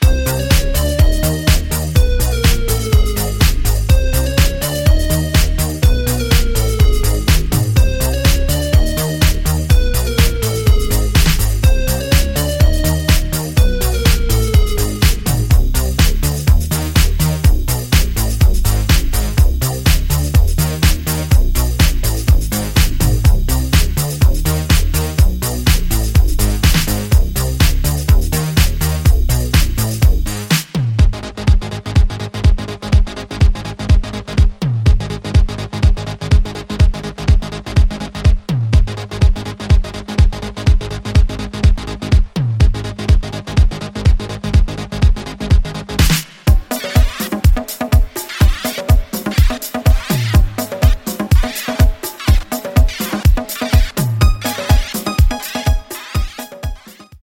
コミカルな80sサウンドをモダンなセンスで昇華した、ナイスな1枚です！